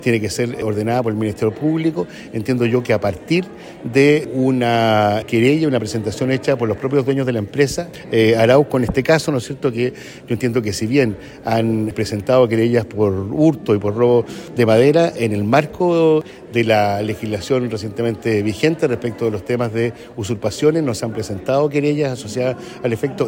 Sin embargo, el delegado presidencial de La Araucanía, Eduardo Abdala, dijo que no tiene las atribuciones para llevar adelante una acción de este tipo. Según dijo, es forestal Arauco quien debe pedir el desalojo y que hasta el momento eso no ha ocurrido.